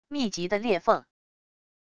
密集的裂缝wav音频